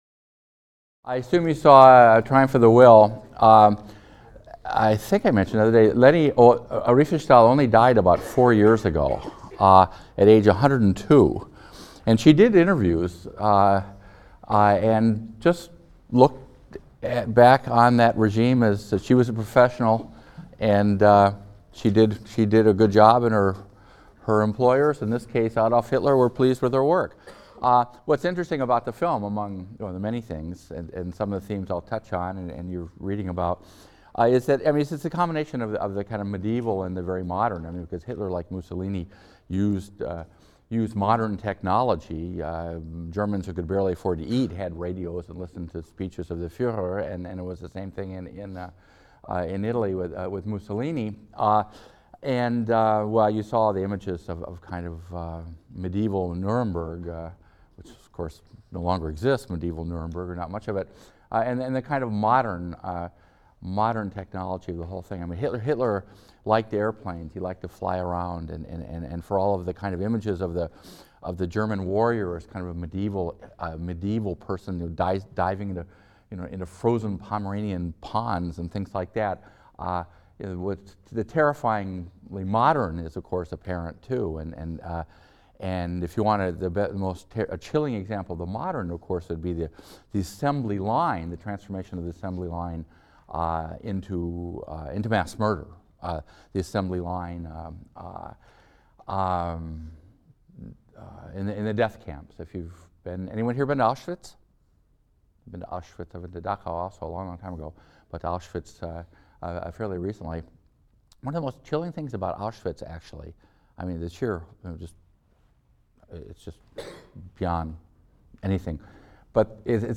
HIST 202 - Lecture 22 - Fascists | Open Yale Courses